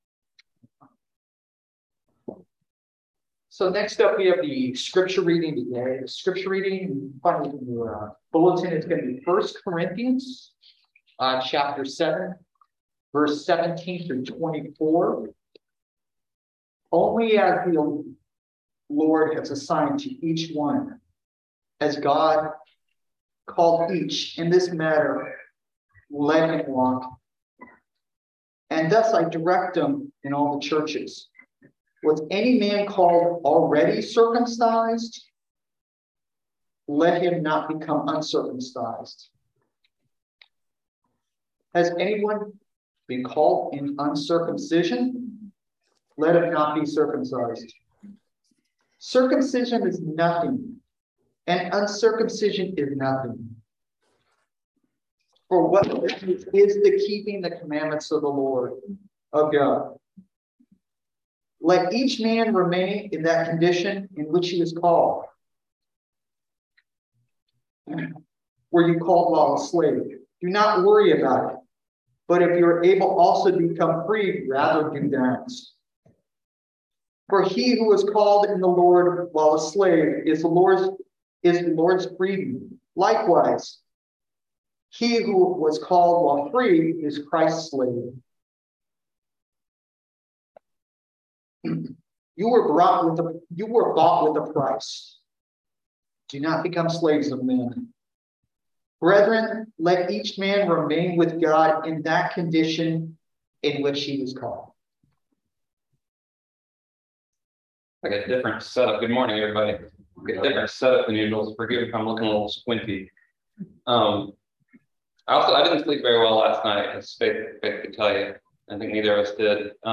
Message for September 11, 2022